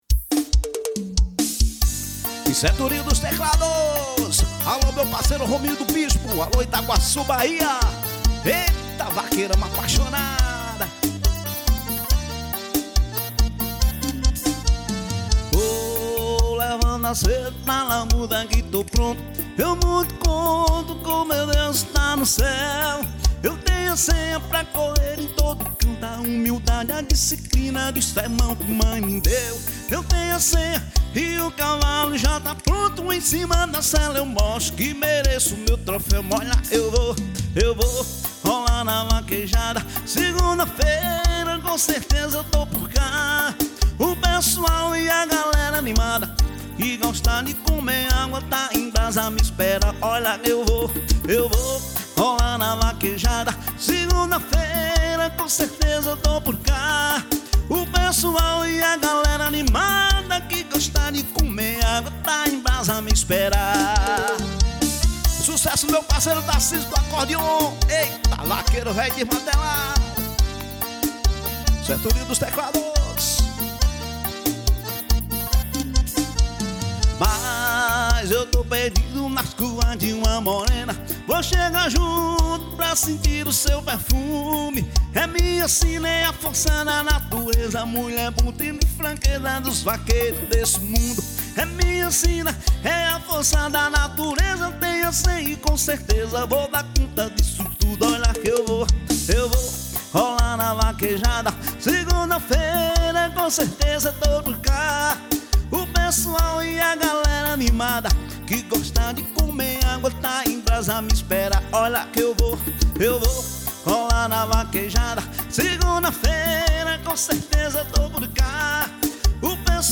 EstiloBrega